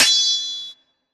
Sword 4.wav